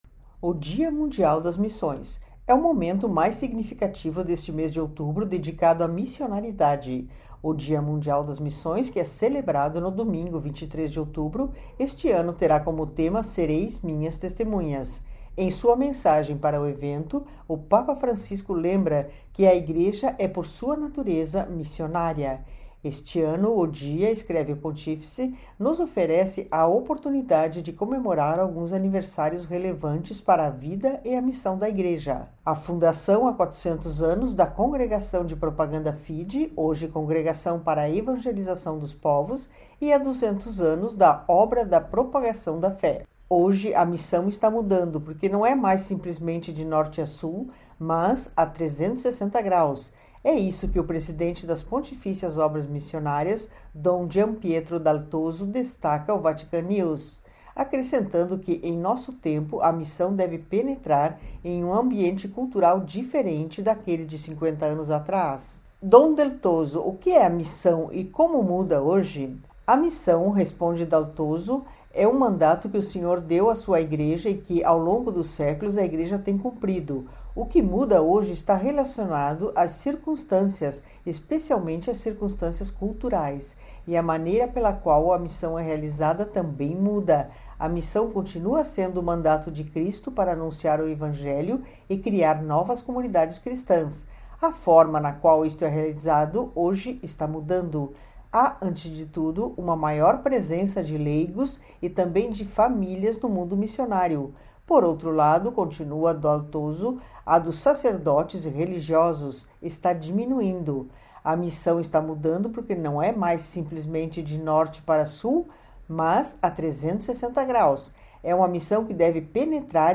Entrevista com o presidente das Pontifícias Obras Missionárias, Dom Giampietro Dal Toso em vista do Dia Mundial das Missões: “A missão continua a ser o mandato de Cristo para anunciar o Evangelho e criar novas comunidades cristãs.